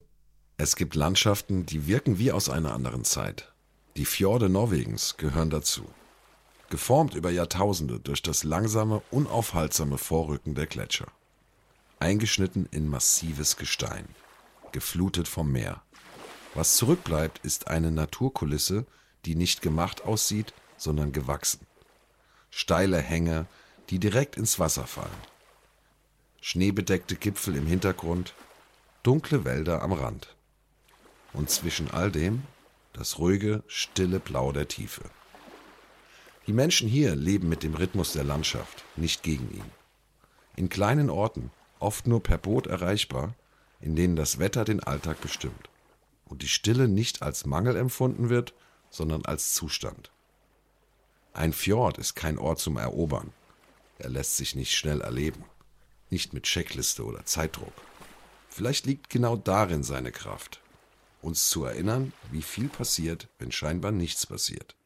Male
My voice ranges from warm and deep to calm and engaging, making it appropriate for a variety of projects.
Documentary
German Documentary 1
1113doku_norwegen_deutsch.mp3